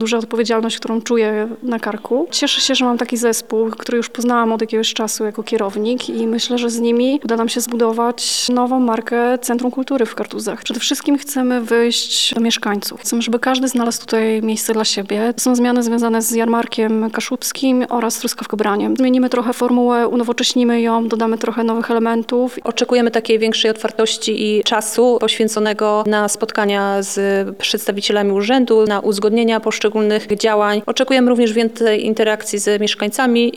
Na konferencji prasowej został przedstawiony plan działania oraz koncepcja rozwoju Kartuskiego Centrum Kultury.